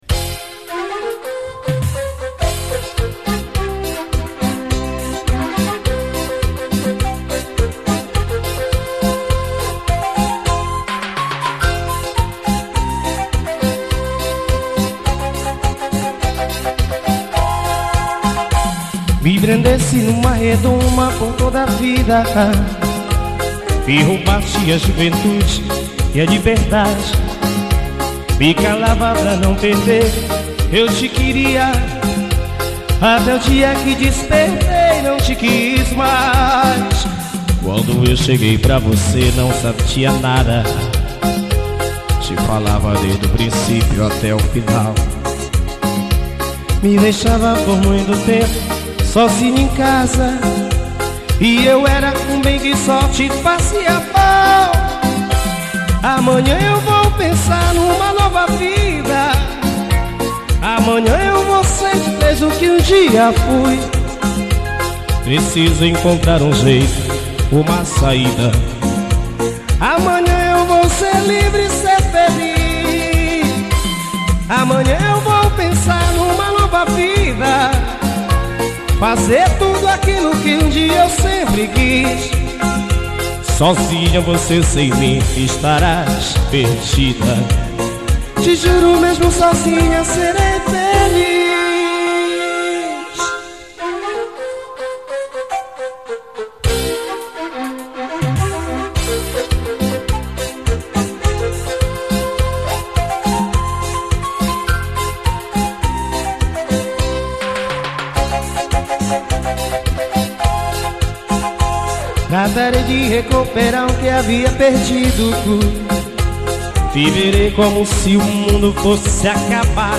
AO VIVO